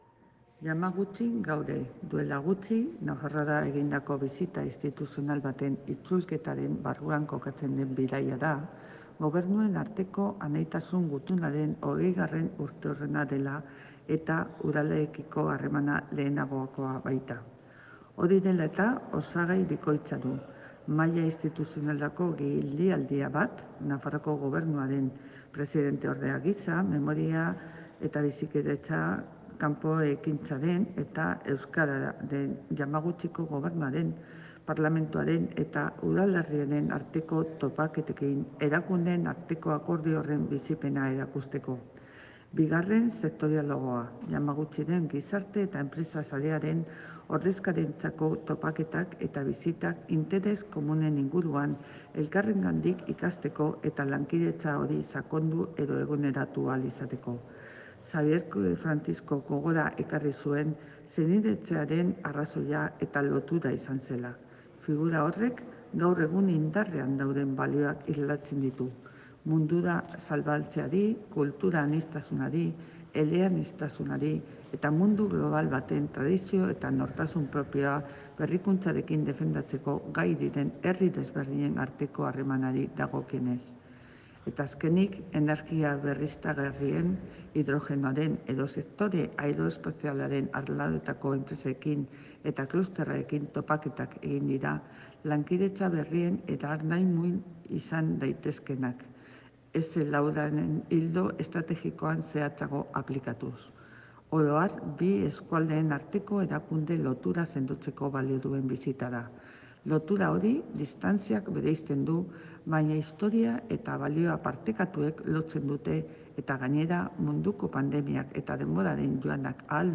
Ana Ollo lehendakariordearen adierazpenak